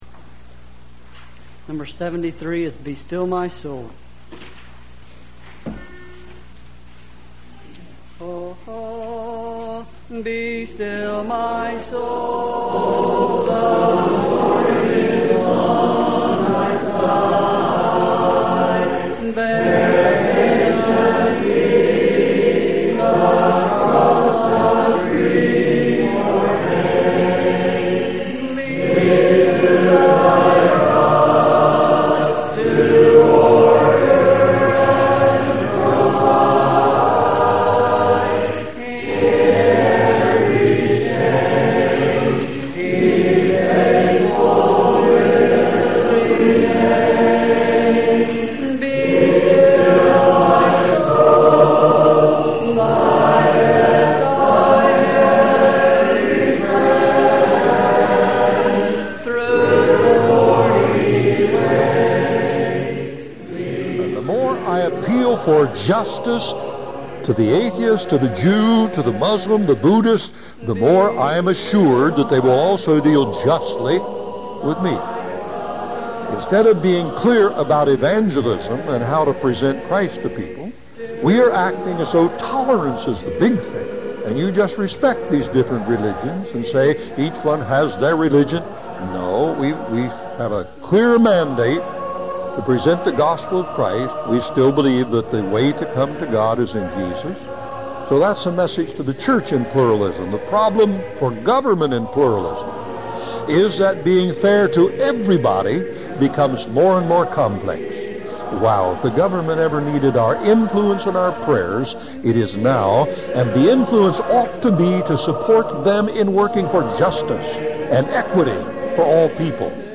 (In Dramatic Stereo/77 min)
chorister